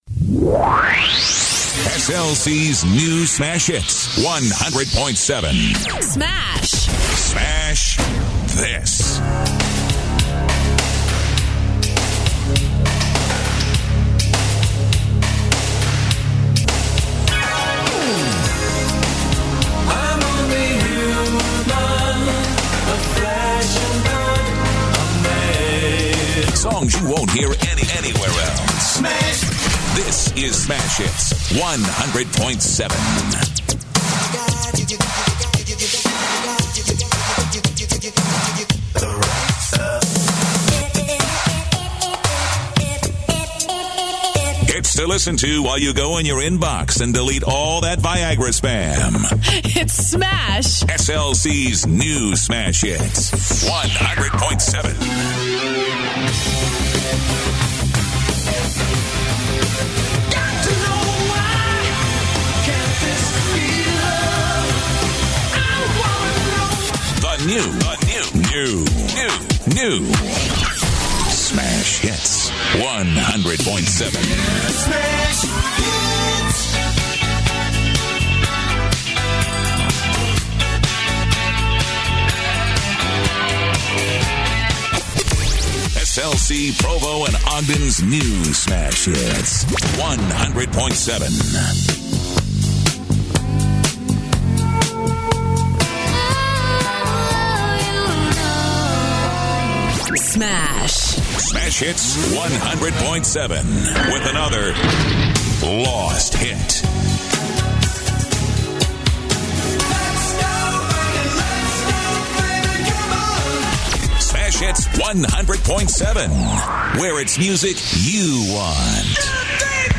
Top 40/Pop Top 40/Pop More Info Close